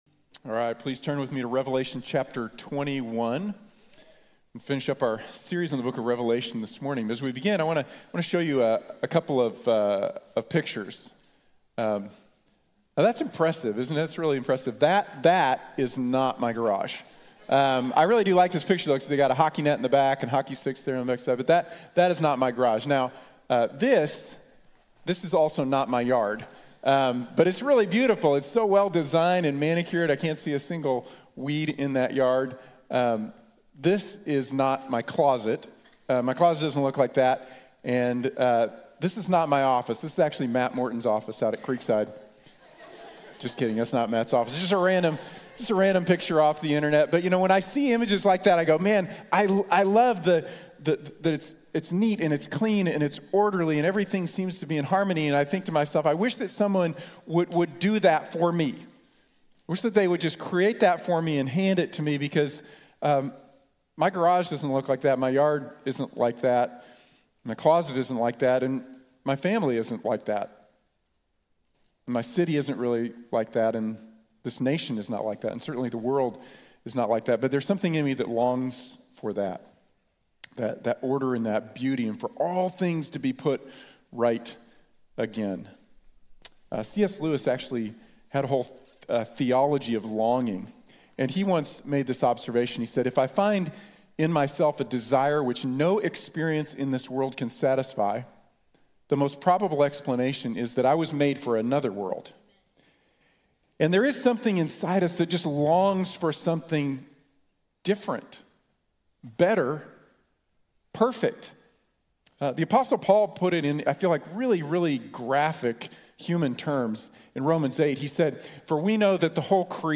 All Things New | Sermon | Grace Bible Church